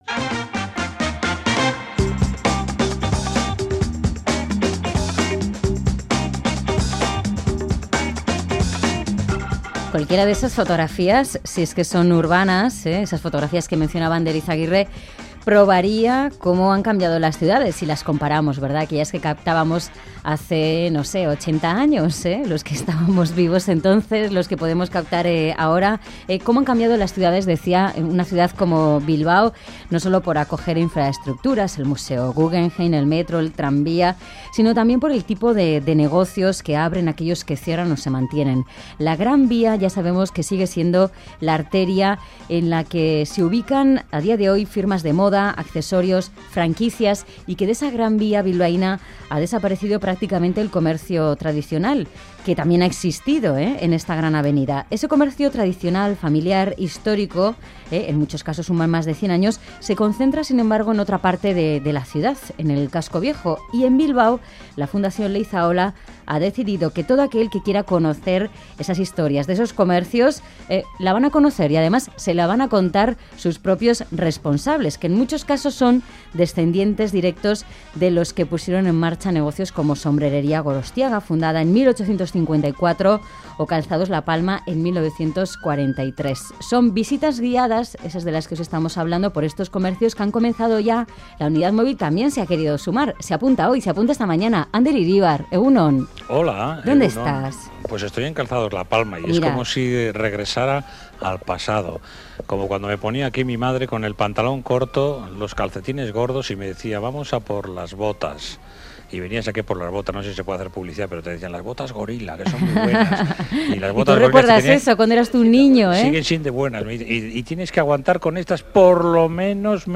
Radio Euskadi BOULEVARD Historia del comercio: La Palma y Gorostiaga Última actualización: 27/06/2014 11:34 (UTC+2) La Fundación Leizaola ha organizado visitas guiadas por los comercios más veteranos e históricos del Casco Viejo de Bilbao. Dos de ellos son Calzados La Palma y Sombrerería Gorostiaga. Nuestra Unidad Móvil ha querido visitarles y admirar su tesón y pasión al frente de comercios que son centenarios.